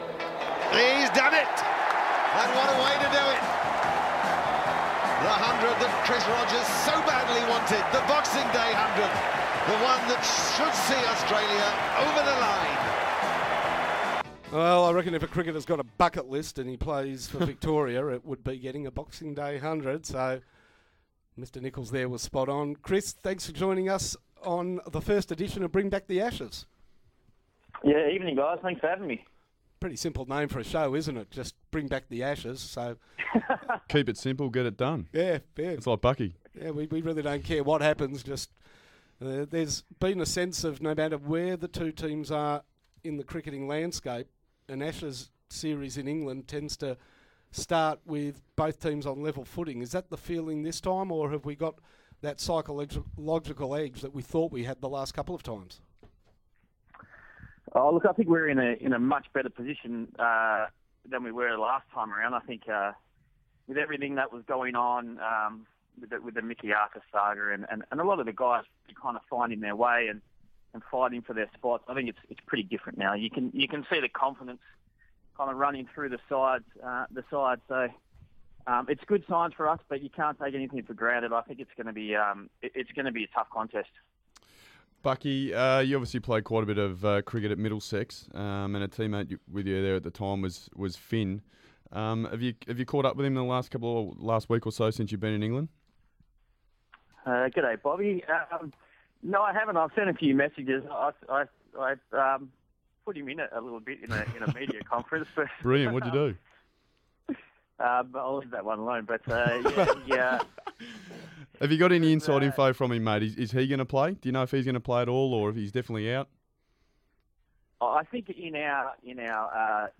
Our new cricket show got off to a great start with Chris Rogers joining Rob Quiney and Dave Hussey live from Cardiff.